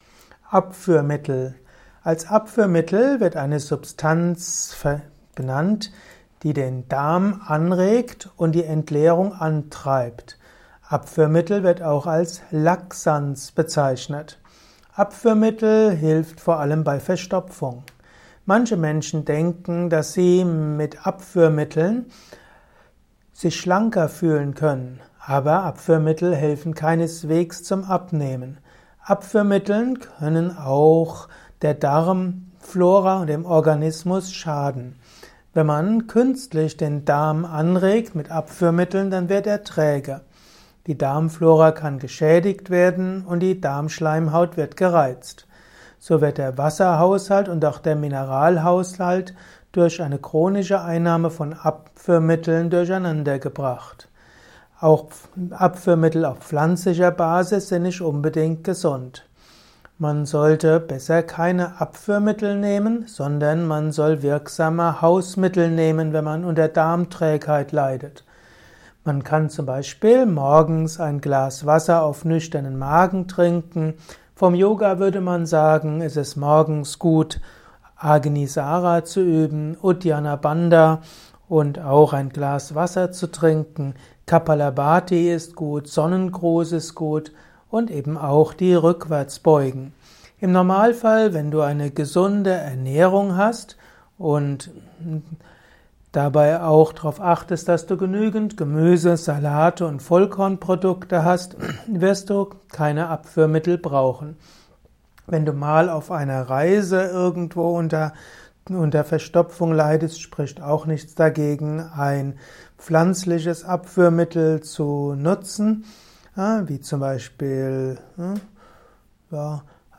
Dieser Vortrag ist ursprünglich konzipiert worden als Diktatgrundlage für den Wiki-Artikel.